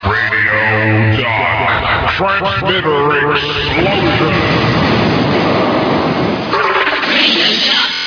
Transmitter explosion 8"
The jingles